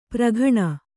♪ praghaṇa